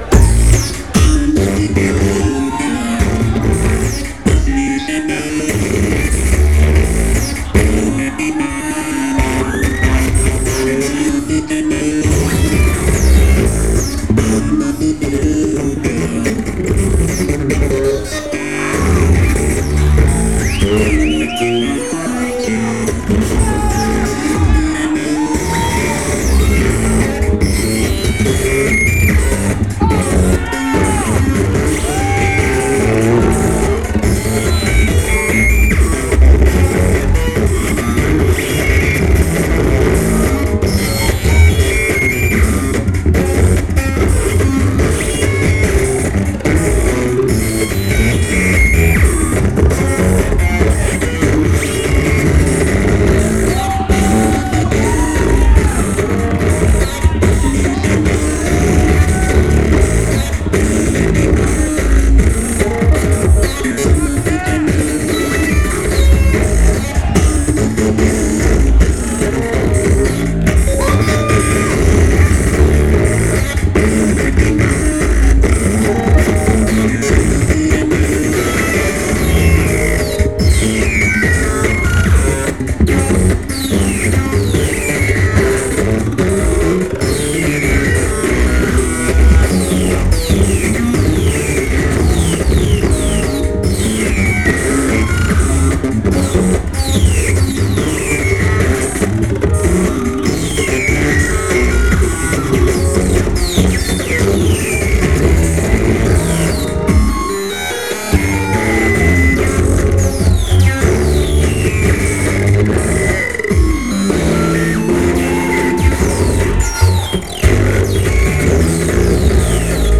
Drill & bass